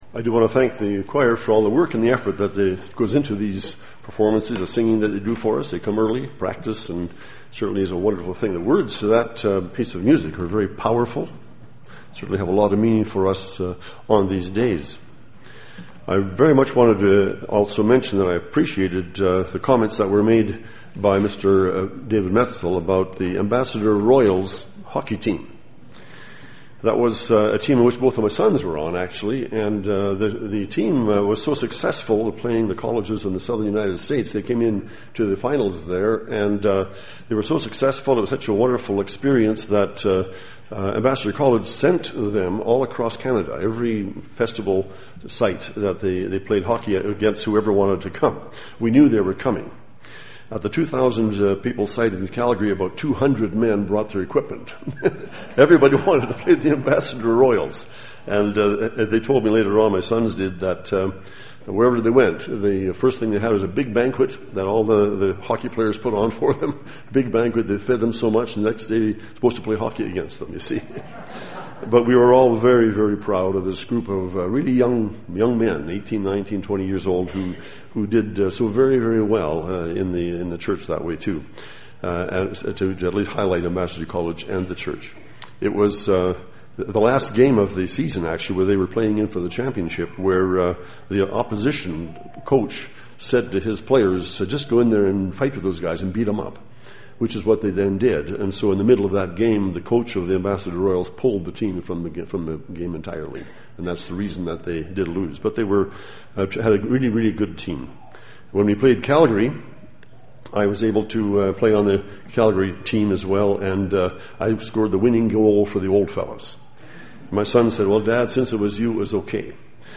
This sermon was given at the Canmore, Alberta 2013 Feast site.